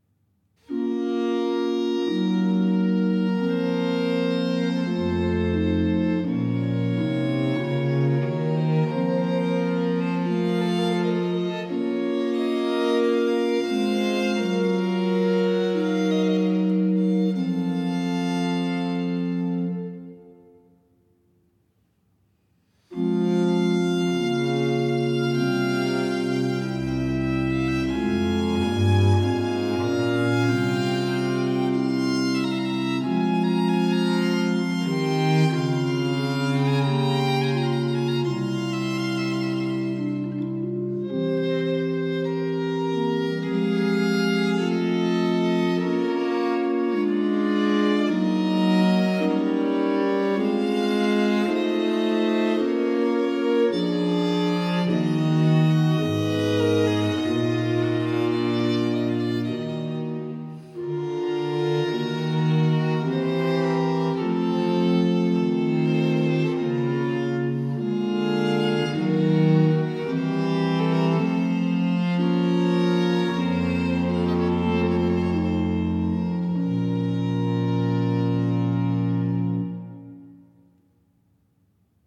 Grave